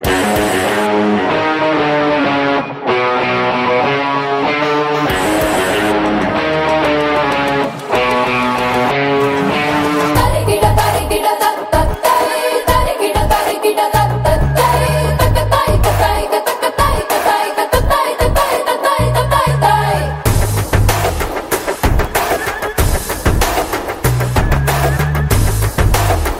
CategoryTelugu Ringtones